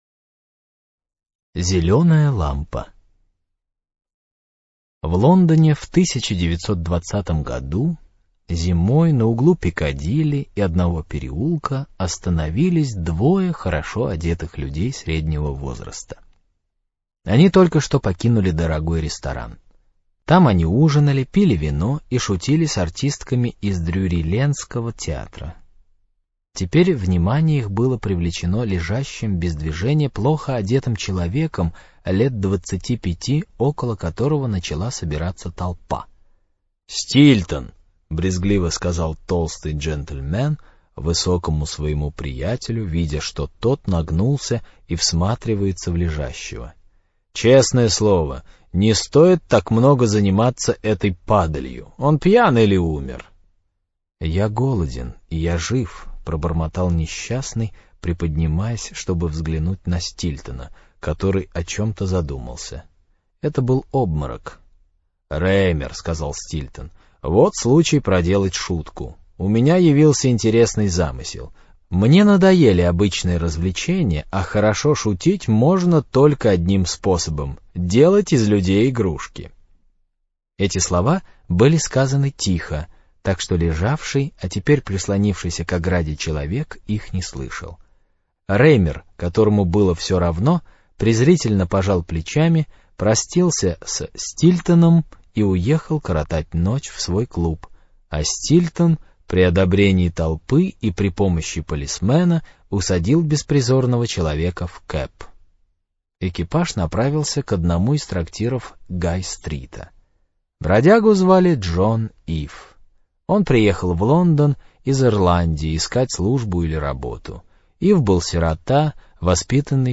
Зелёная лампа - аудио рассказ Грина - слушать онлайн